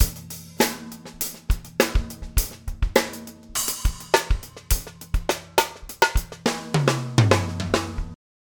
Drums – trocken
effekte_beim_recording_vs._mixing_effekte___drums.mp3